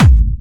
VEC3 Clubby Kicks
VEC3 Bassdrums Clubby 006.wav